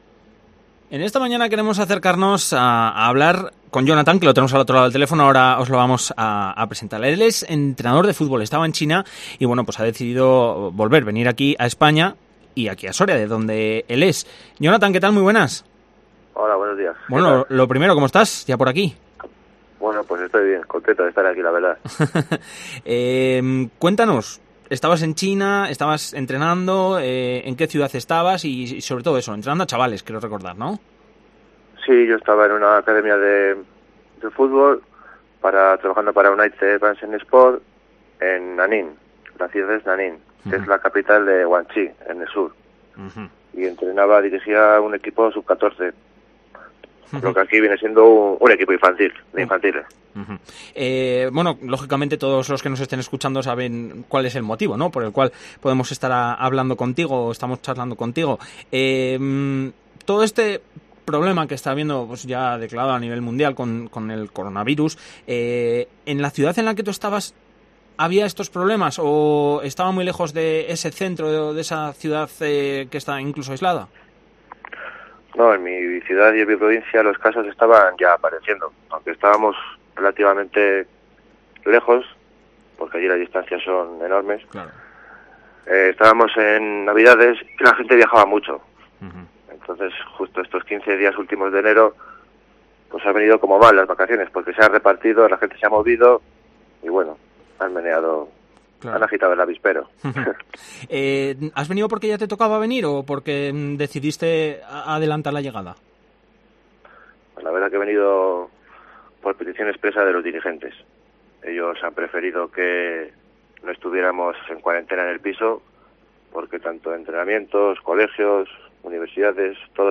ENTREVISTA. El regreso de un soriano desde Nanning (China) donde estaba viviendo.